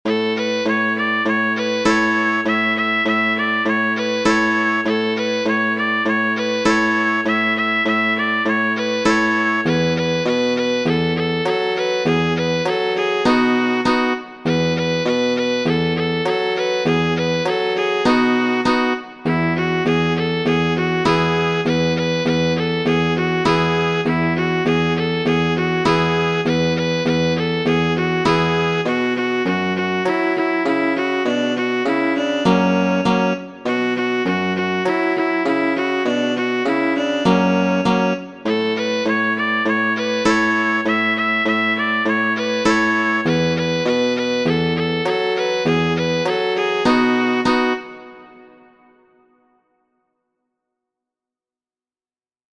| Kajero 33ª | En PDF (paĝo 3ª) | Kajeroj | Muziko: Danco, renajsanca.
danco.mp3